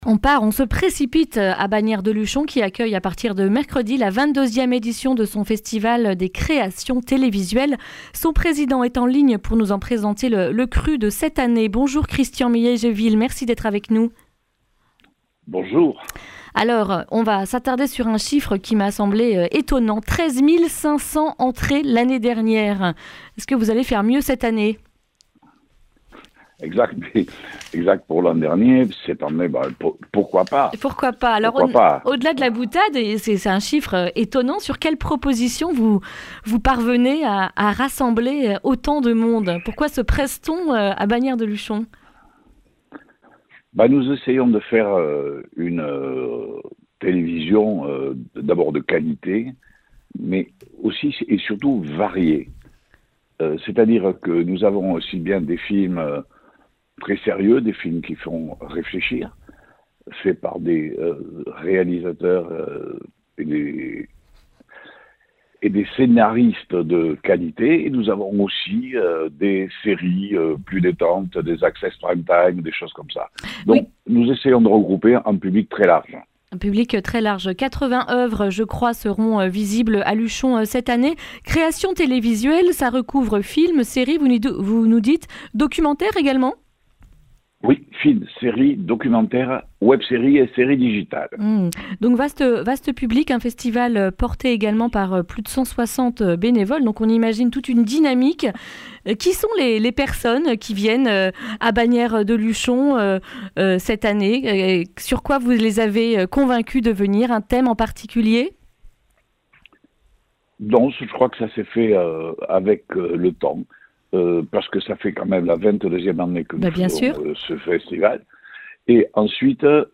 lundi 3 février 2020 Le grand entretien Durée 11 min